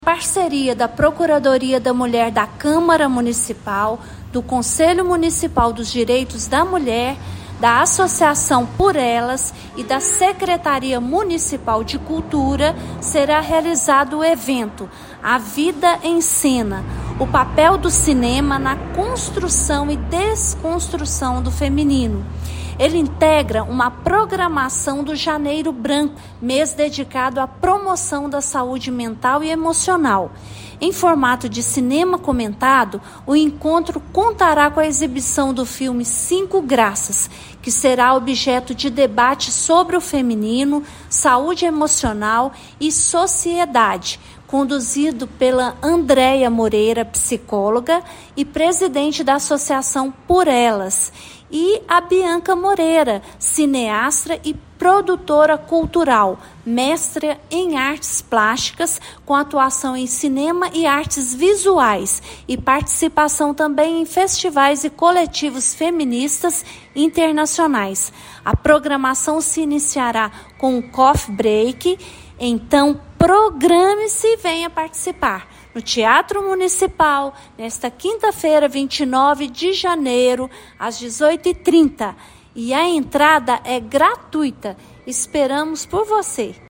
Irene Susana da Silva de Melo Franco, vereadora, vice-presidente da Câmara Municipal e presidente da Procuradoria da Mulher do Legislativo paraminense, reforça o convite para que os cidadãos ocupem o teatro nesta quinta-feira. De acordo com a parlamentar, o evento é uma oportunidade de unir cultura e autocuidado em um ambiente acessível e enriquecedor: